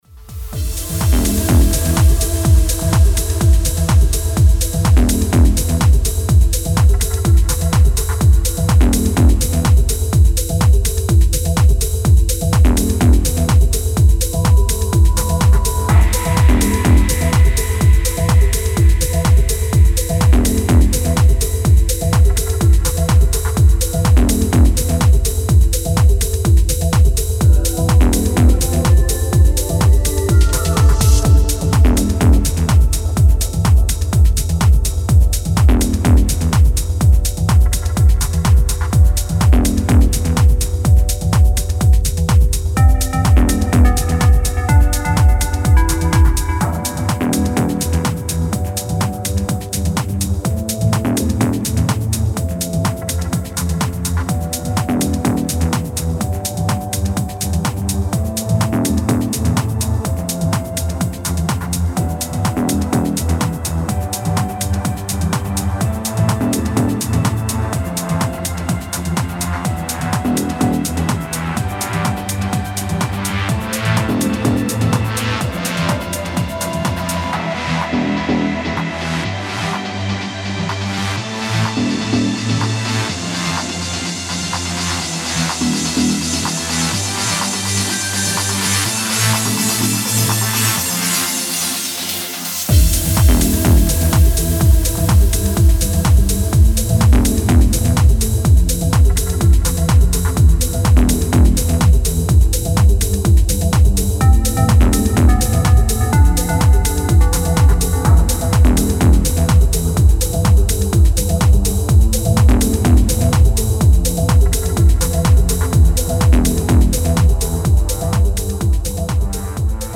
Hypnotising Remix Pack
Style: Techno / Tech House
Instrumental